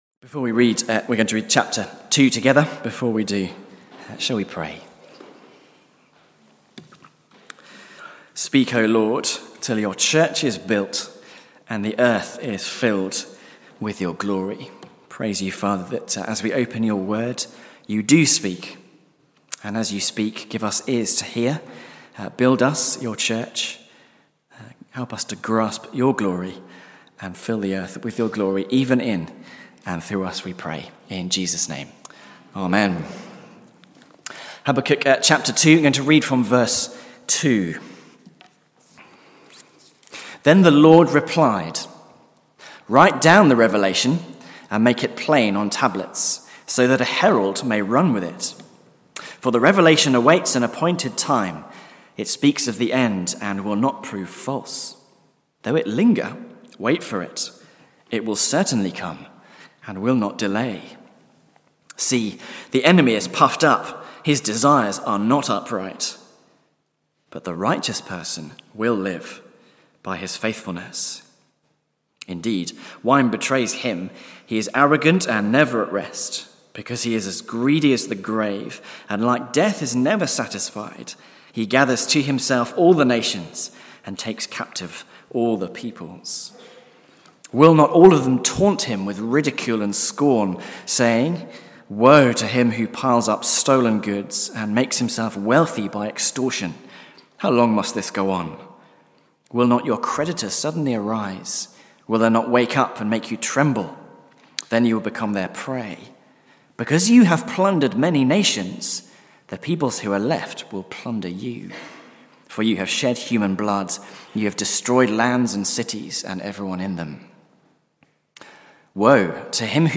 Sermons Archive - Page 30 of 188 - All Saints Preston